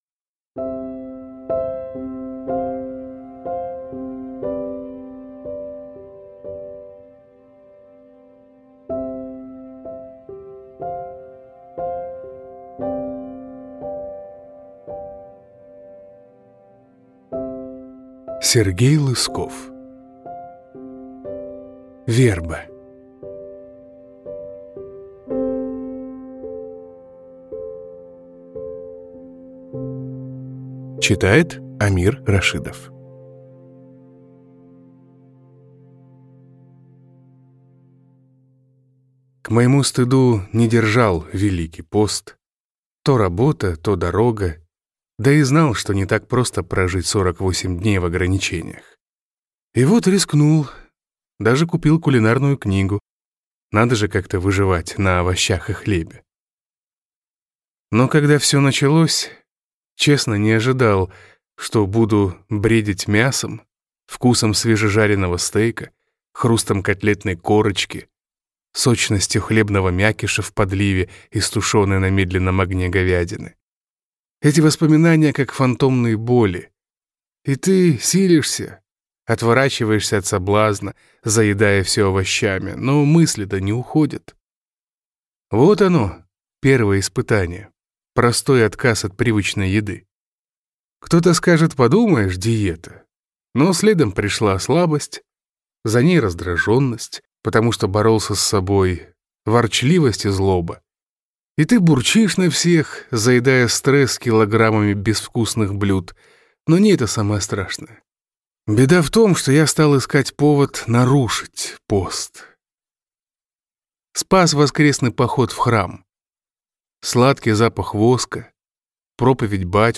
Аудиокнига Верба | Библиотека аудиокниг